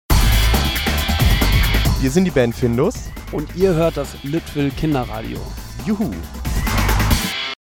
station-id-findus.mp3